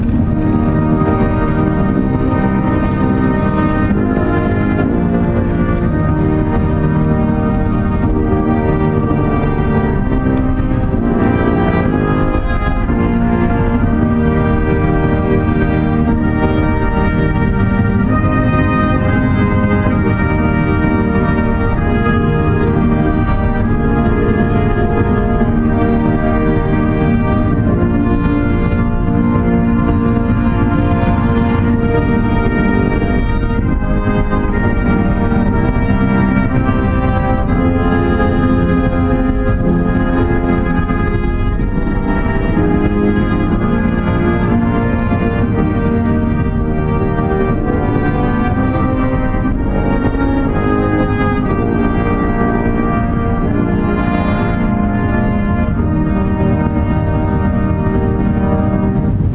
"Schönster Herr Jesu" performed by the United States Army Band in December 2018.flac